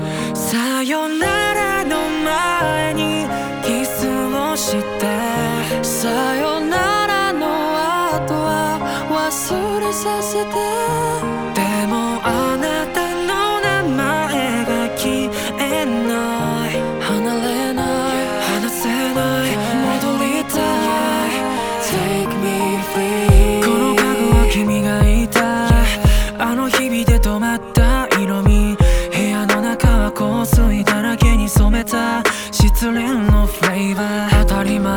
Жанр: Поп / Электроника / J-pop